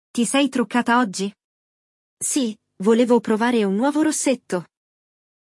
Neste episódio, você ouvirá um diálogo entre duas amigas, onde uma delas repara que a outra está maquiada e faz um elogio.
Exemplo de diálogo